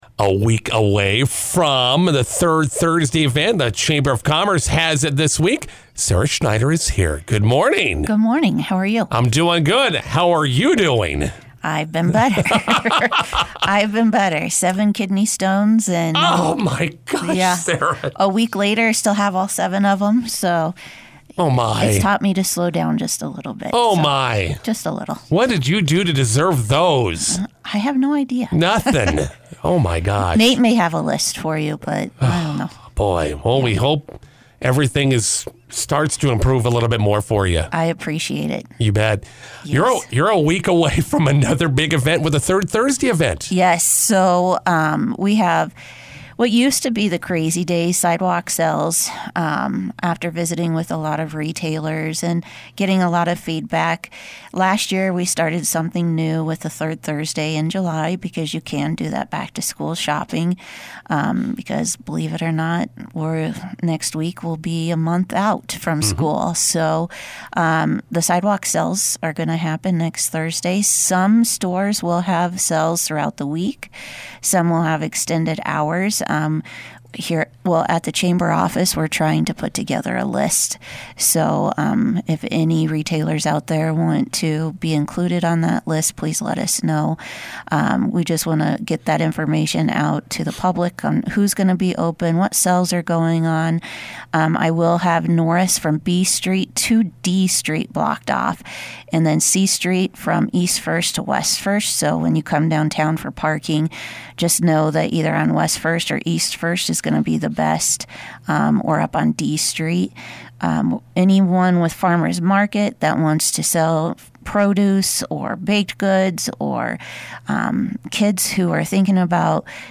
INTERVIEW: McCook Chamber of Commerce Third Thursday Family on the Bricks Night coming up this week.